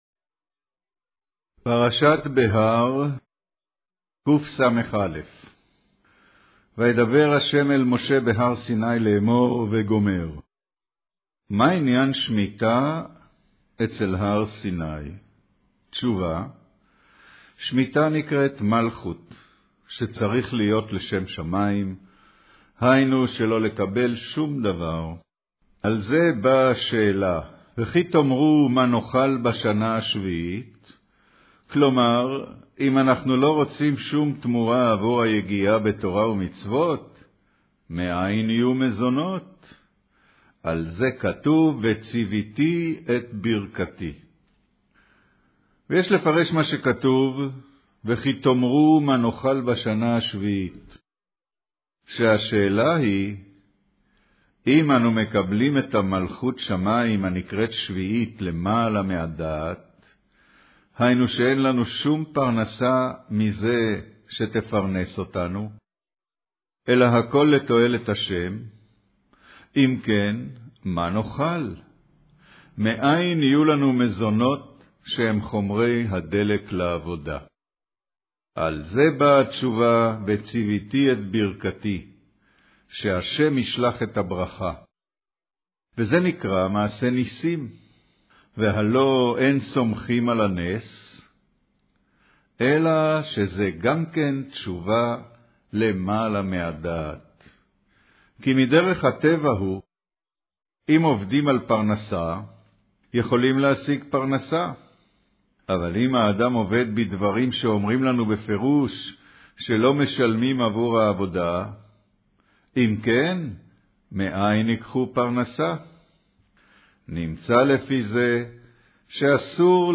אודיו - קריינות פרשת בהר